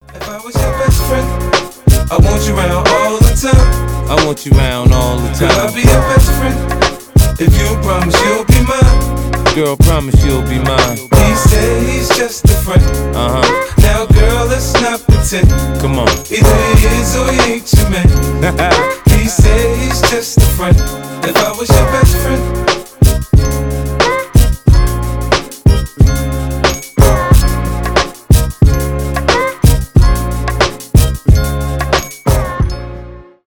хип-хоп
gangsta rap